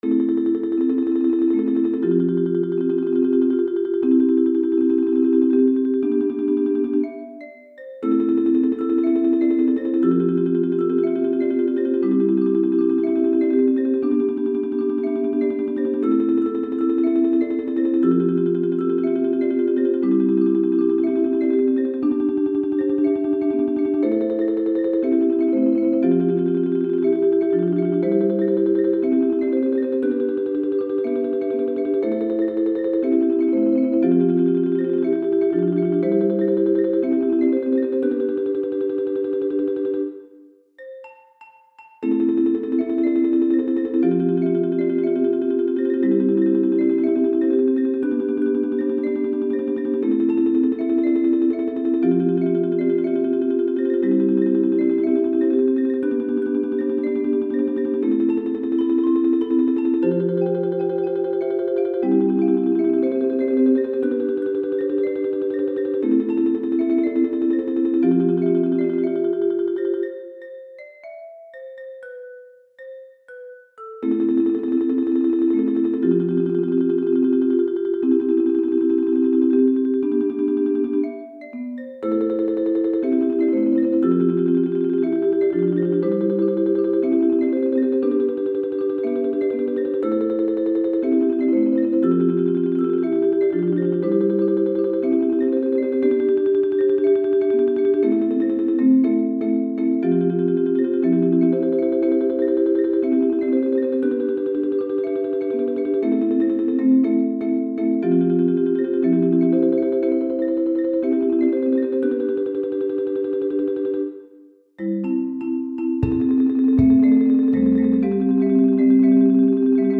Mallet-Steelband
Xylofoon Marimba Percussion Drumstel